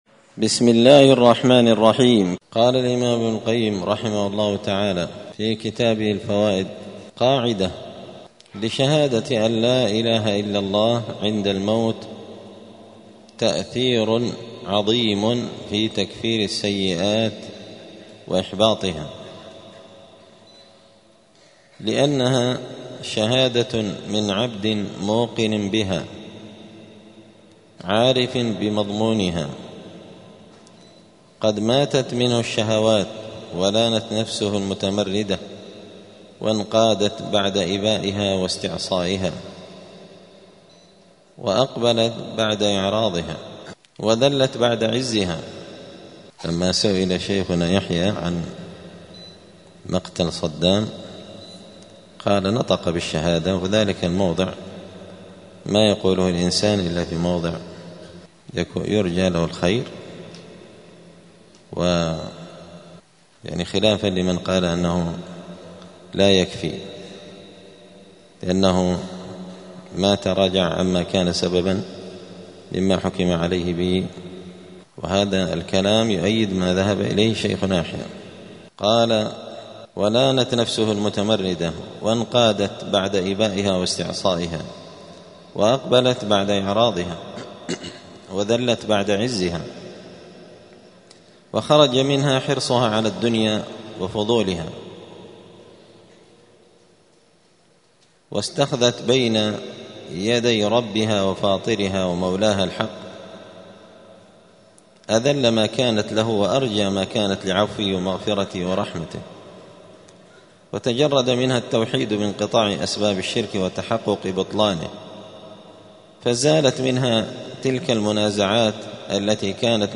*الدرس الثلاثون (30) {فصل: شهادة أن لا إله إلا الله مكفرة للسيئات}*
الجمعة 5 جمادى الآخرة 1446 هــــ | الدروس، دروس الآداب، كتاب الفوائد للإمام ابن القيم رحمه الله | شارك بتعليقك | 32 المشاهدات